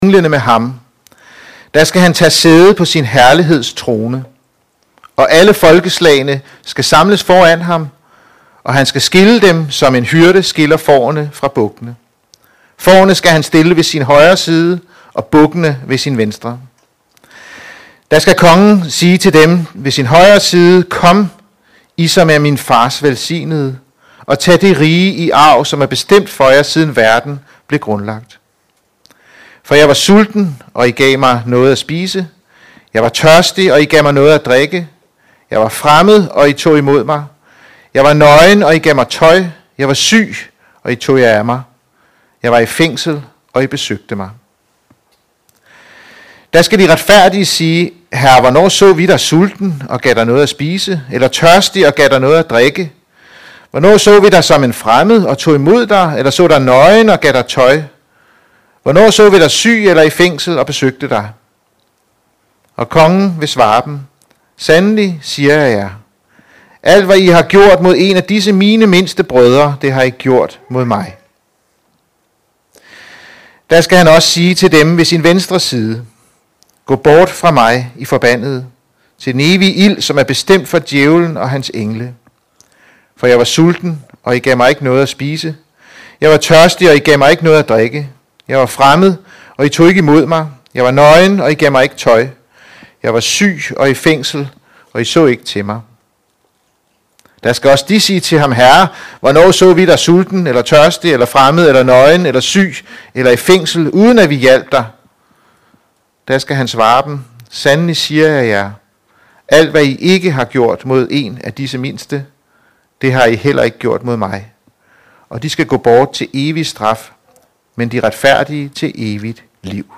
Sidste søndag i kirkeåret – Prædiken
sidste-soendag-i-kirkeaaret-praediken.mp3